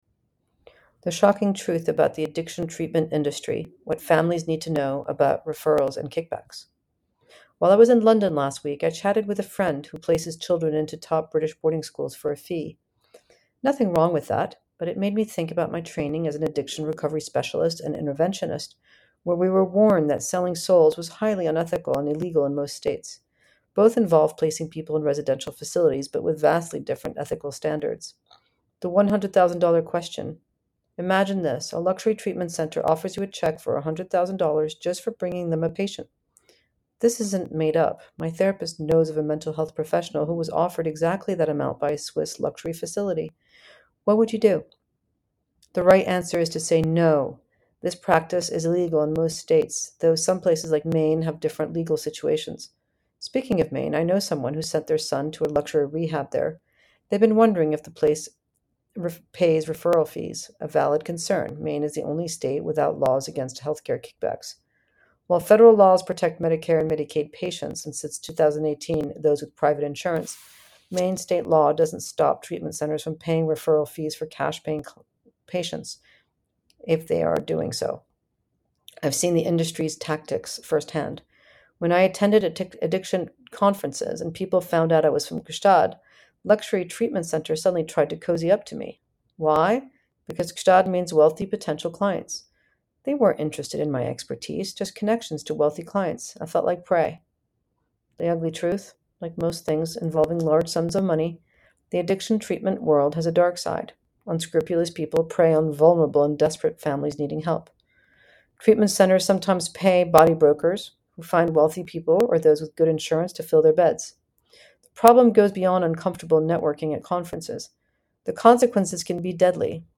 A narrated essay from The Pressures of Privilege.